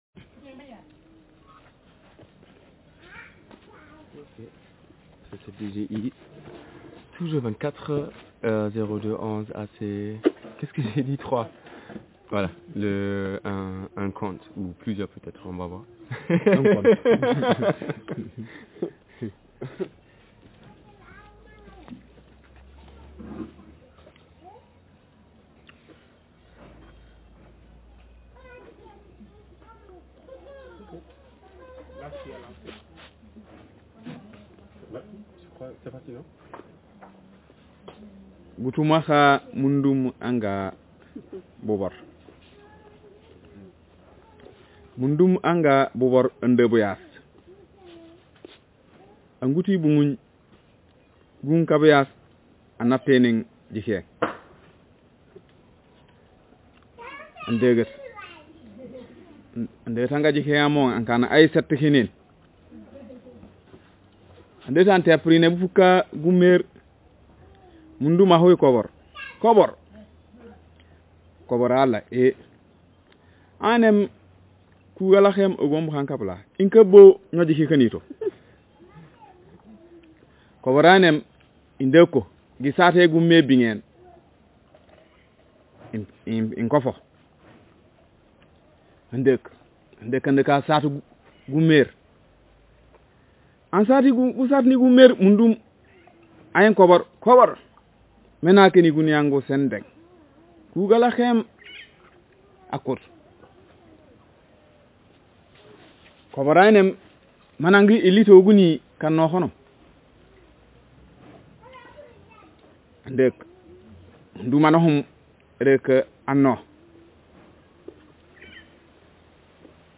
Speaker sexf/m
Text genreconversation